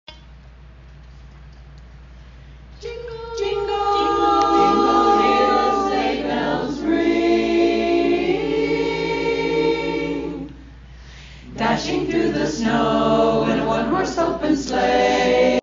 Simply A Cappella - Holiday Singing Grams